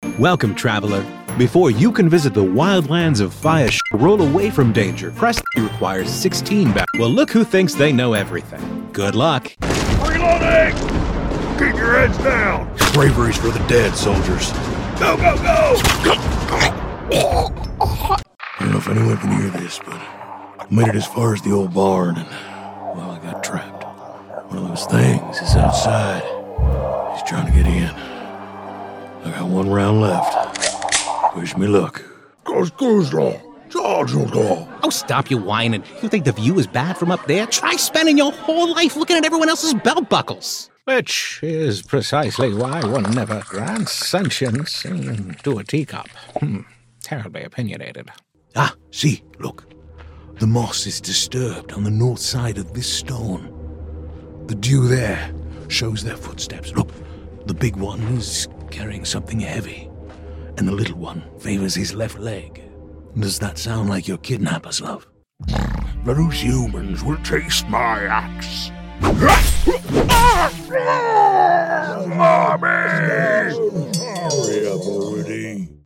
Demos & Reels
Home studio w/ Stellar X2 Vintage Mic
Gaming Demo